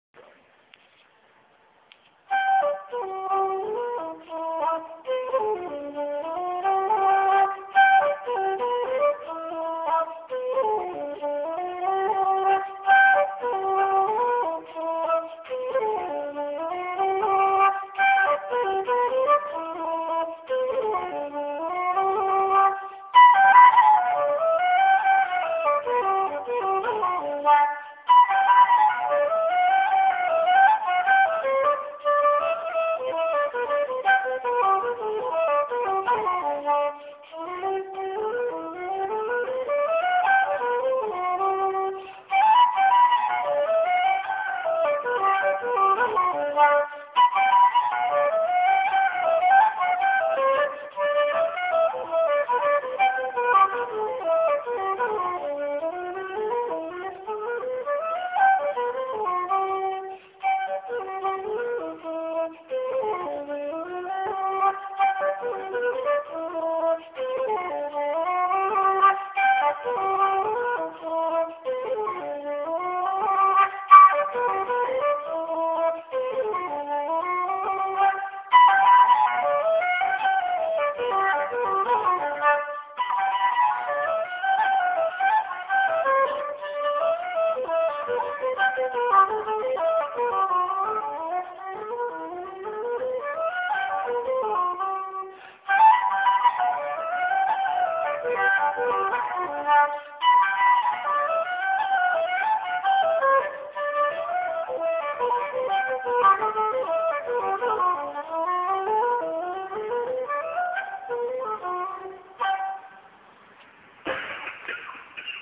Flute - Poor Recording on my phone, Good enough though http